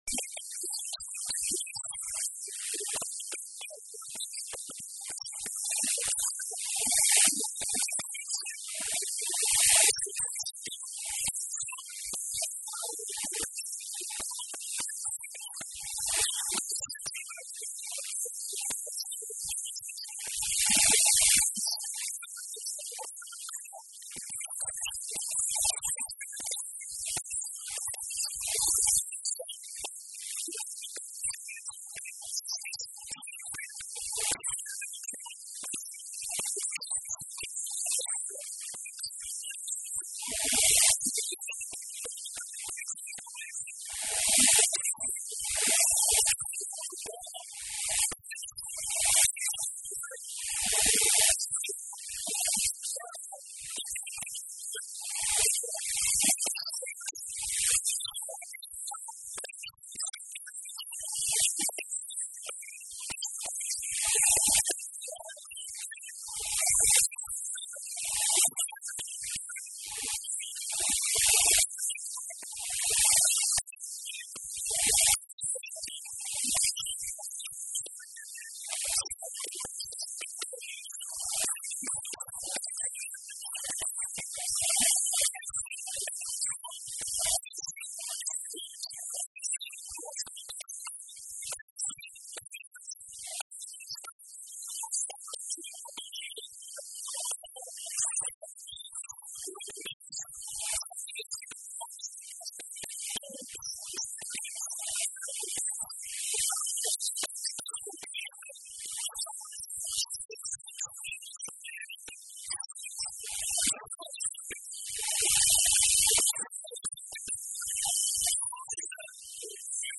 “Não tenho receio absolutamente nenhum de recuar em iniciativas, propostas, medidas do Governo Regional sempre que isso for em benefício dos Açorianos e das Açorianas”, afirmou Vasco Cordeiro, numa intervenção no plenário da Assembleia Legislativa, durante o debate de propostas de alteração ao Estatuto do Ensino Particular, Cooperativo e Solidário.